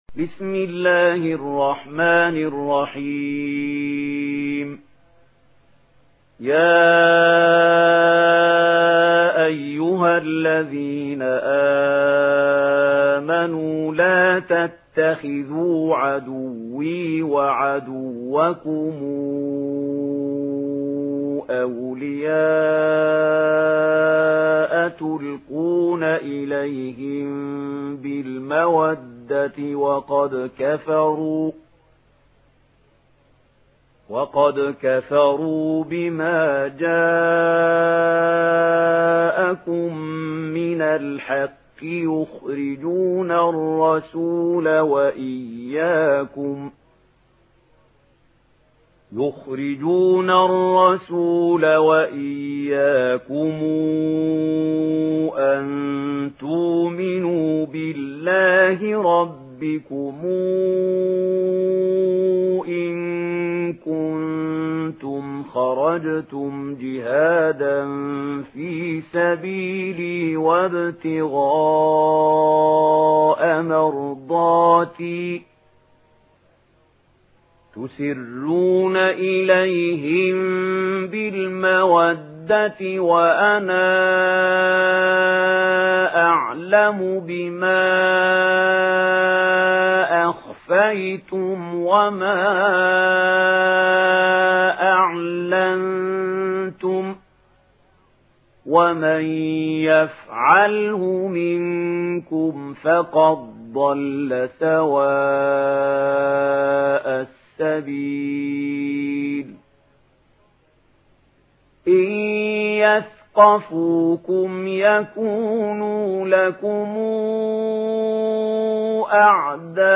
Surah الممتحنه MP3 by محمود خليل الحصري in ورش عن نافع narration.